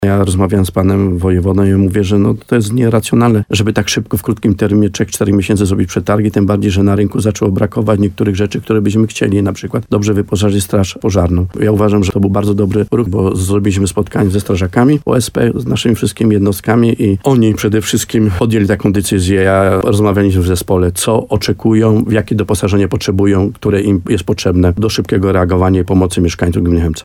– Gmina Chełmiec ma pieniądze na obronę cywilną – zapewniał w programie Słowo za Słowo w radiu RDN Nowy Sącz wójt Stanisław Kuzak.